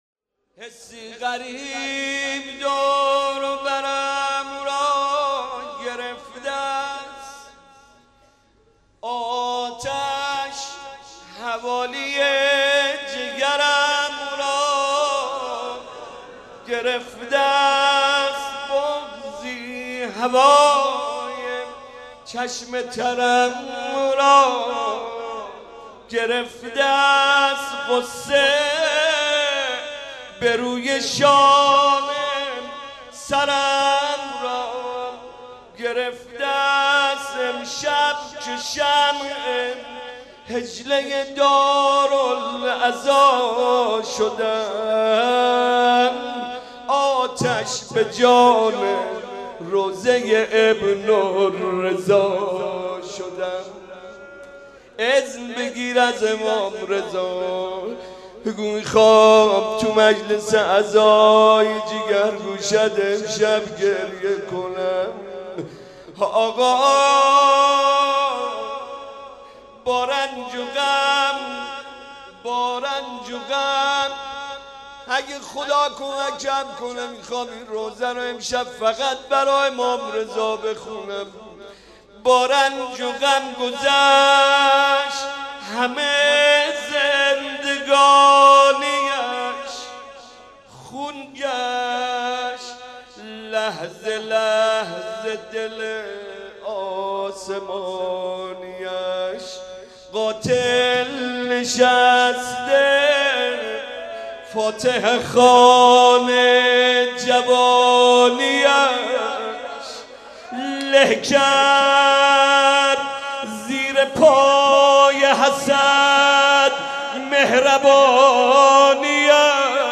گلچین روضه ی حضرت امام جواد علیه السلام